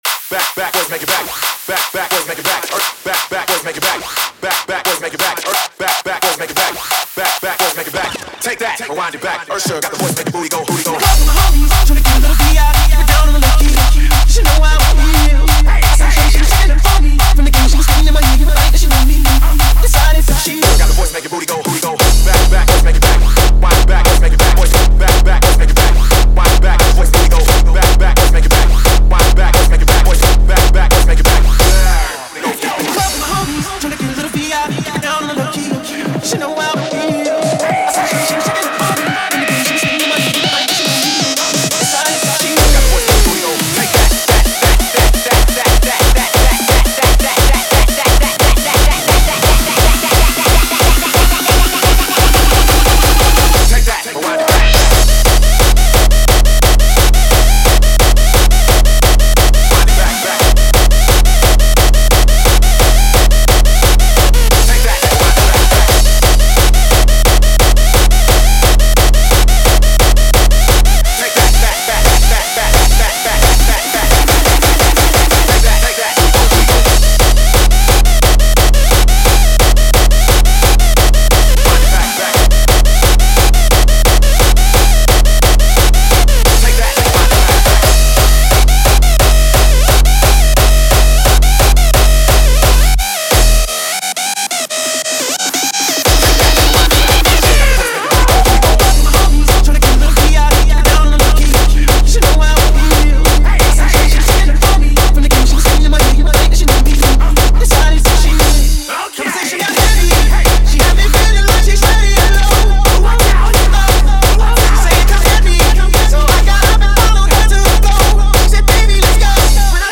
Género: Newstyle.